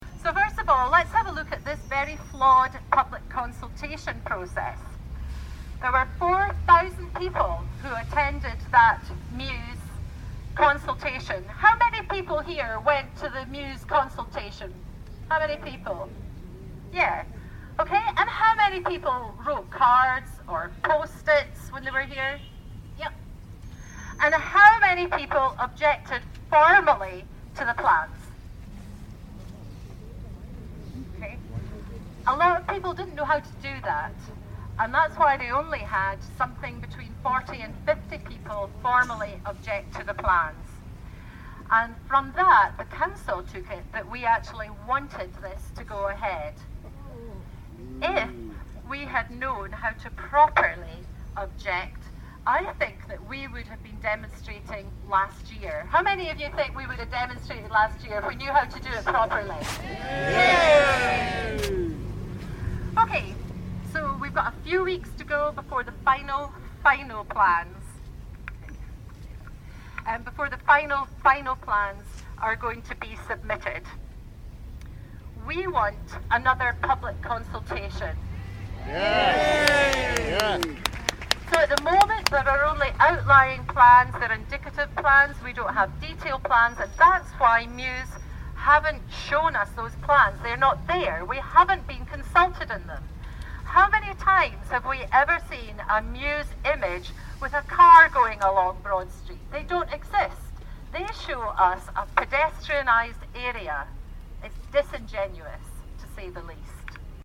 Hundreds of demonstrators who gathered outside Aberdeen Council Headquarters have called for another public consultation on the Marischal Square project.